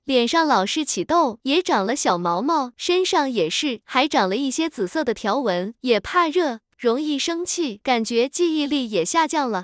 tts_result_2.wav